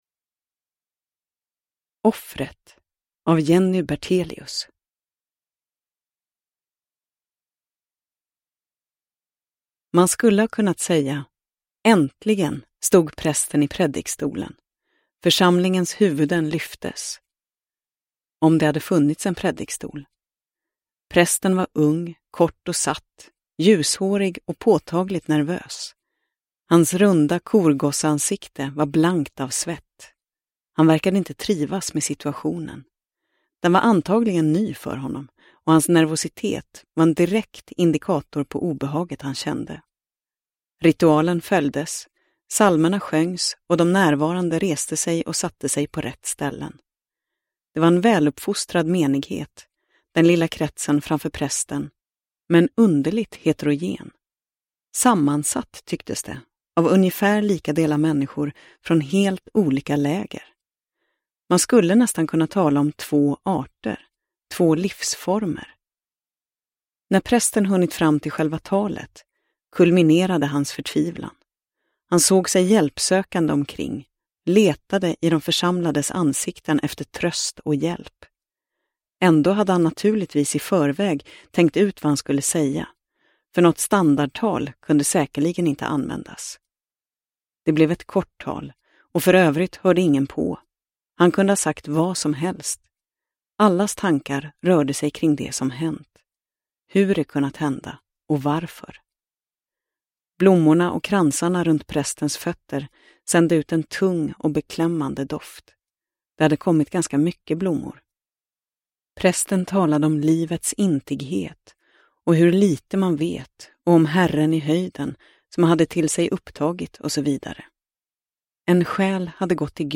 Offret – Ljudbok – Laddas ner